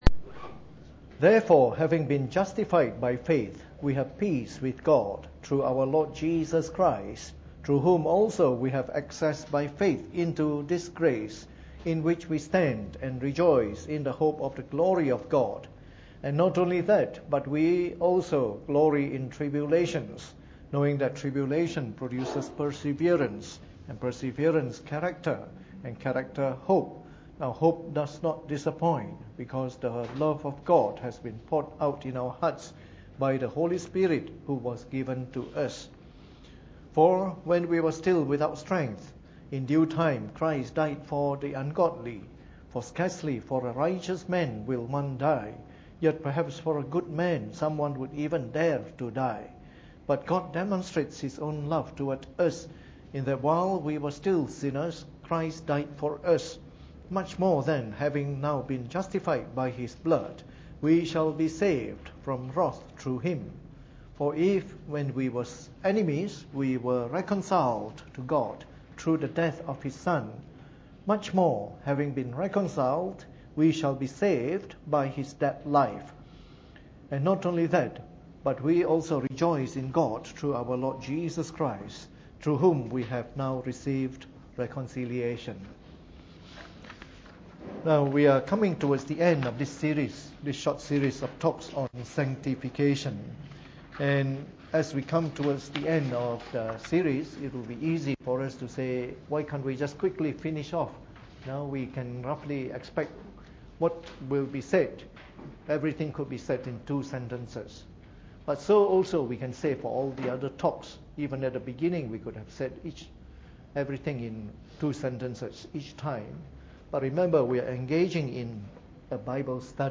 Preached on the 4th of March 2015 during the Bible Study, from our series of talks on Sanctification.